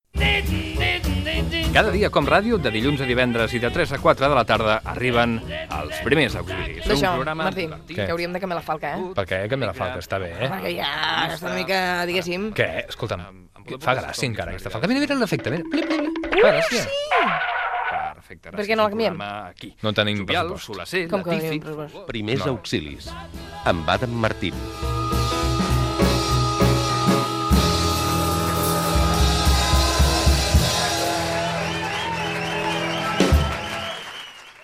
Promoció del programa
Fragment extret de l'arxiu sonor de COM Ràdio